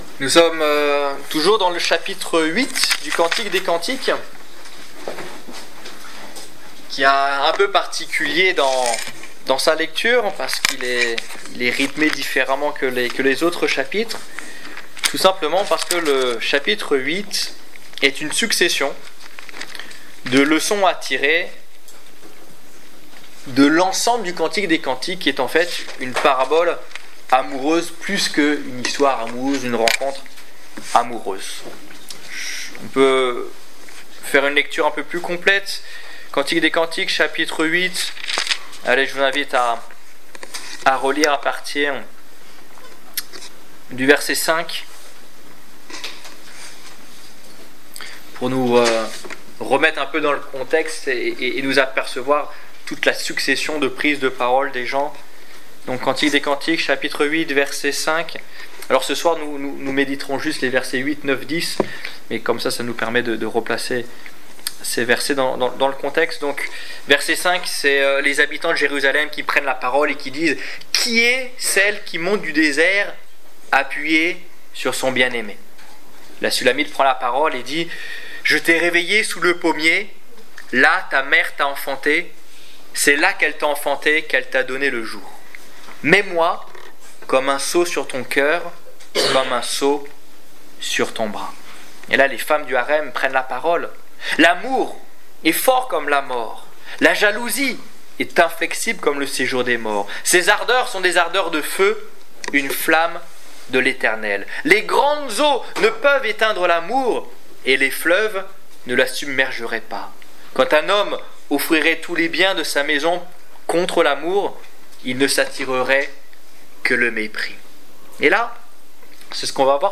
Étude biblique du 28 octobre 2015